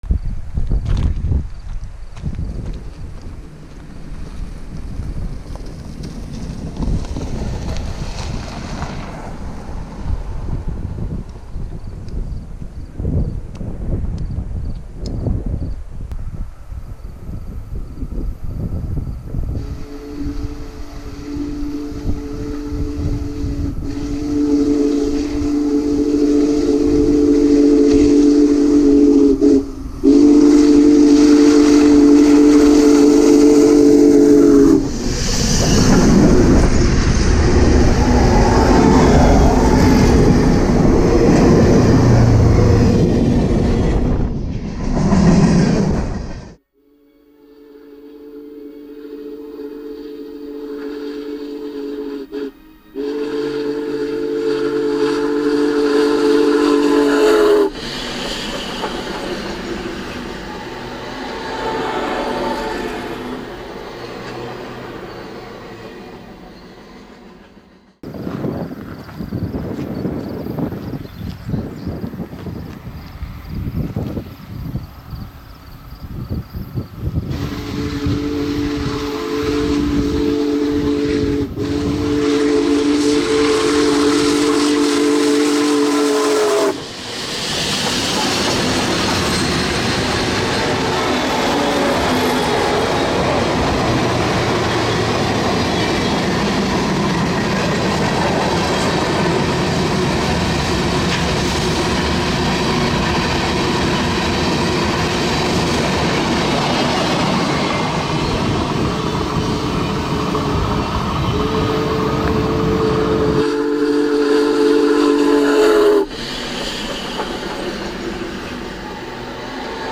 Union Pacific #4014 (Big Boy) in Nebraska (June 2023)